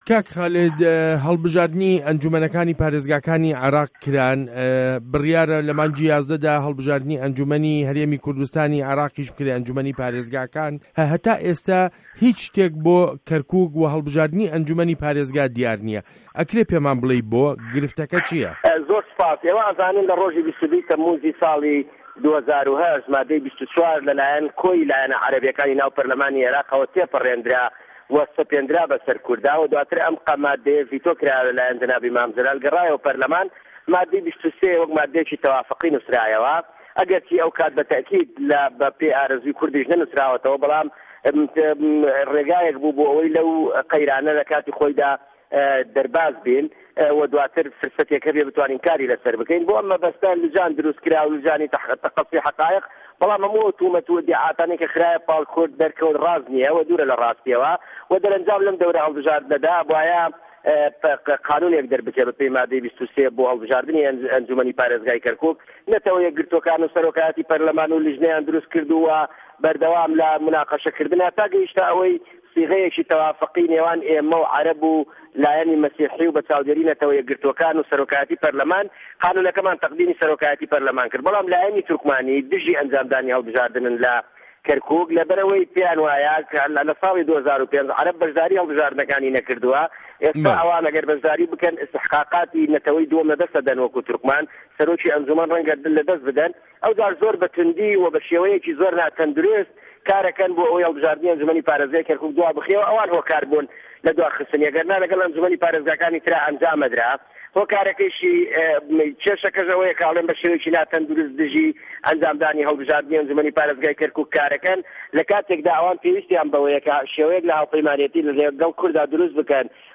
وتووێژ له‌گه‌ڵ خالد شوانی